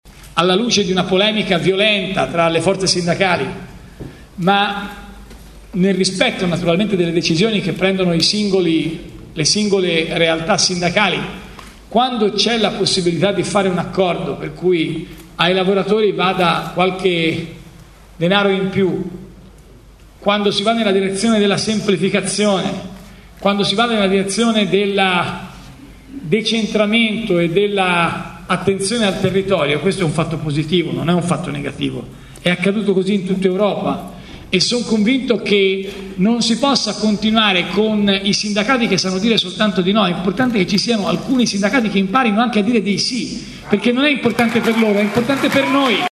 Nessuna alleanza con Casini, ribadisce Renzi, ma anche “basta con i partitini di centro”. Poi una critica anche al mondo sindacale e alla Cgil: “Sono convinto che non si possa continuare con i sindacati che sanno dire soltanto di no”, ma la sala risponde un po’ più freddamente.